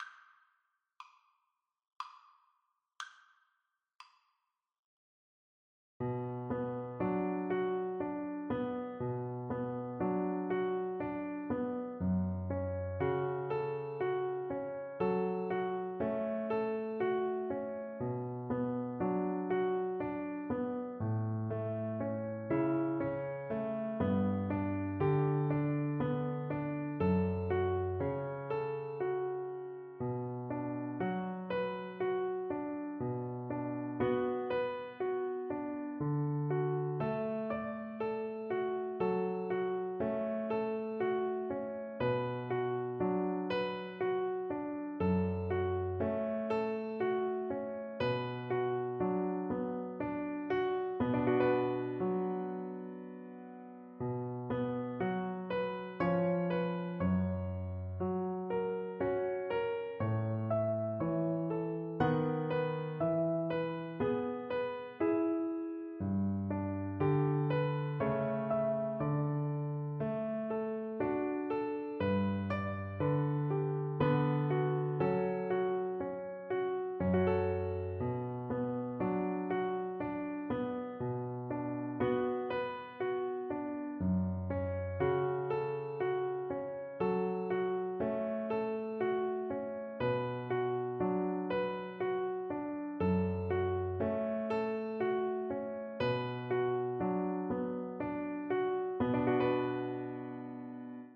Viola
Traditional Music of unknown author.
3/4 (View more 3/4 Music)
B minor (Sounding Pitch) (View more B minor Music for Viola )
Moderato